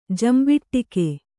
♪ jambiṭṭike